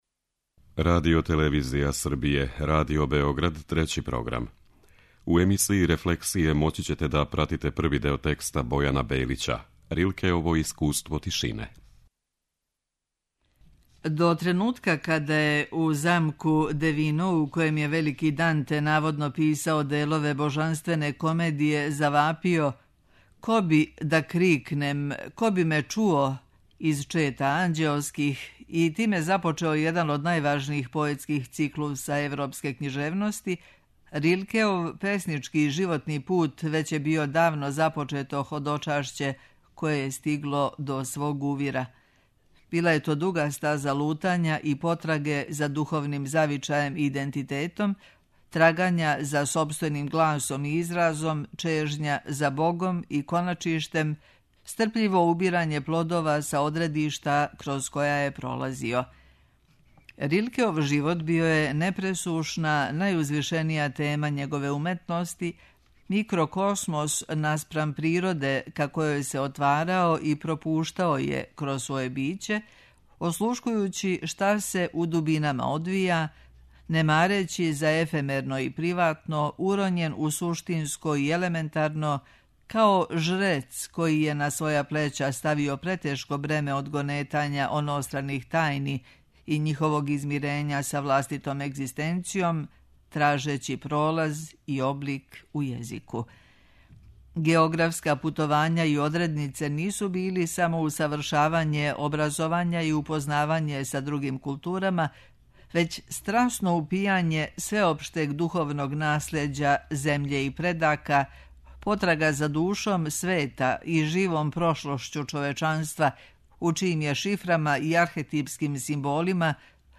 преузми : 20.56 MB Рефлексије Autor: Уредници Трећег програма У емисијама РЕФЛЕКСИЈЕ читамо есеје или научне чланке домаћих и страних аутора.